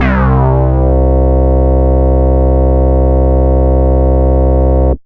Odessey Bass.wav